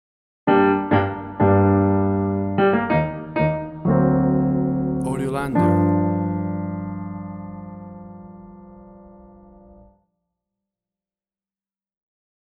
A mysterious piece of sitcom piano used as a scene change!
WAV Sample Rate: 16-Bit stereo, 44.1 kHz